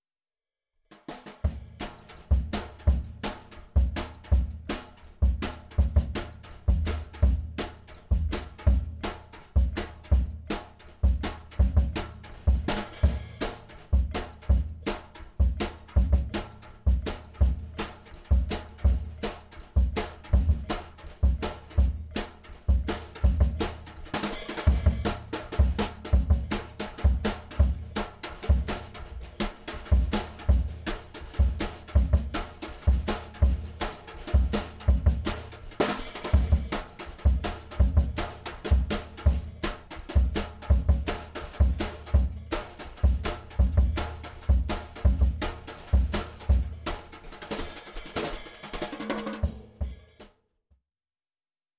Drum Overhead - LCT 440 PURE